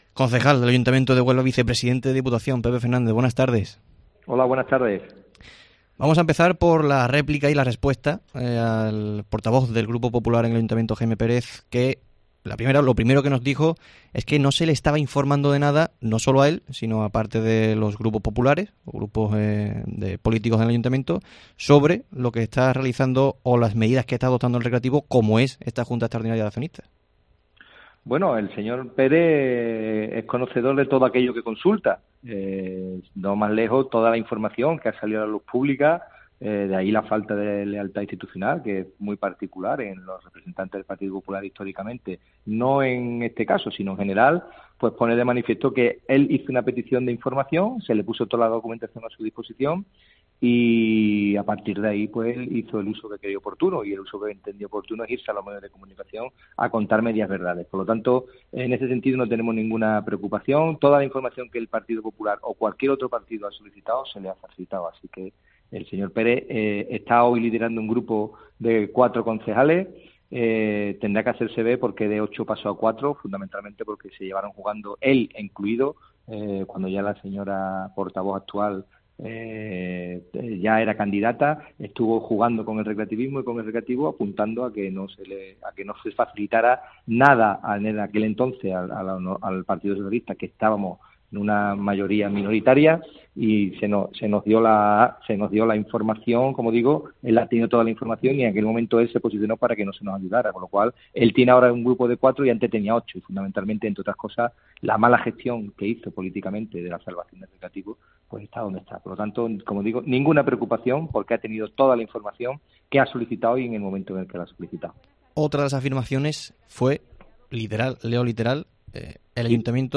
El concejal del Ayto. de Huelva y vicepresidente de la Diputación, Pepe Fernández, nos concede una entrevista para analizar la situación actual del...